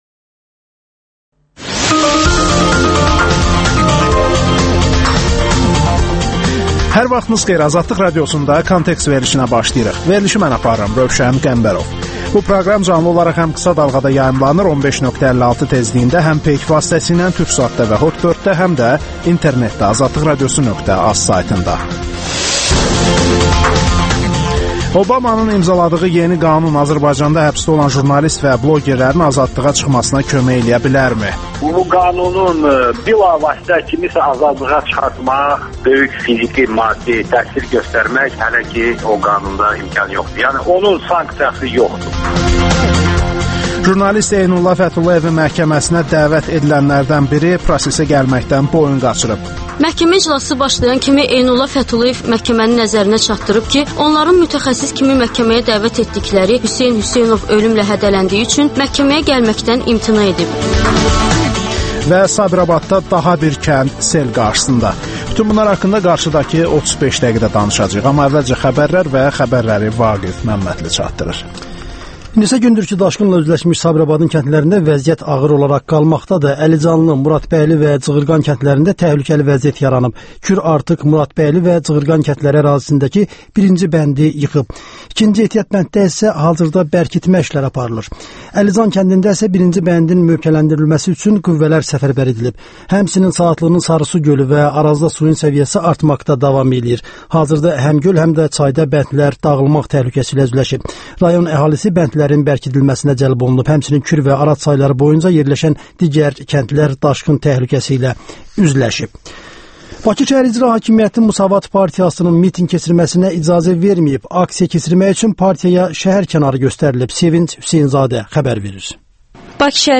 Xəbərlər